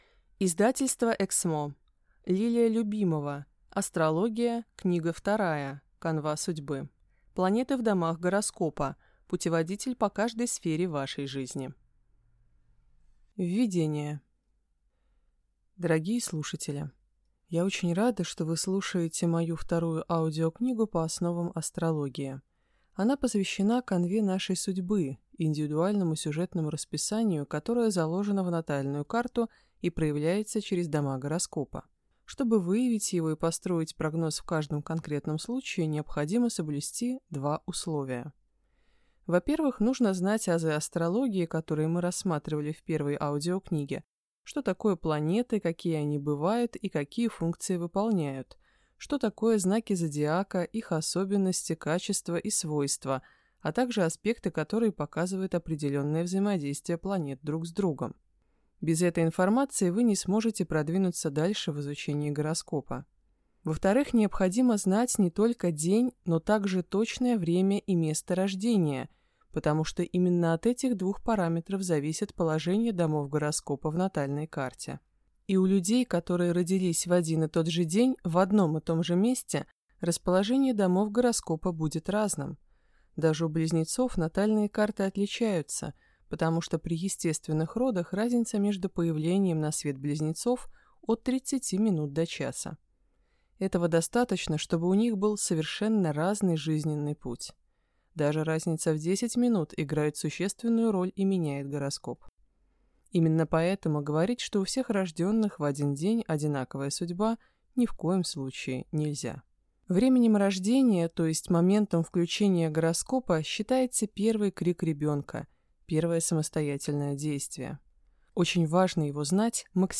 Аудиокнига Астрология. Книга II. Канва судьбы. Планеты в домах гороскопа: путеводитель по каждой сфере вашей жизни | Библиотека аудиокниг